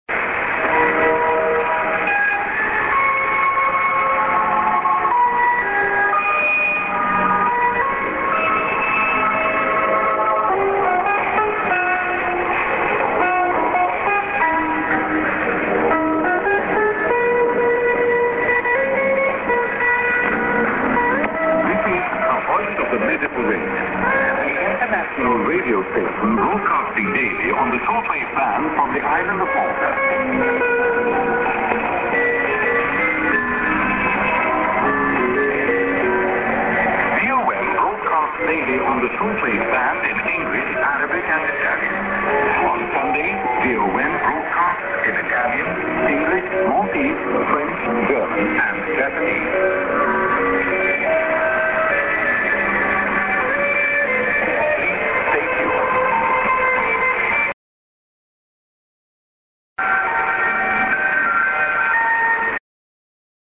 St. music->ID+(man)->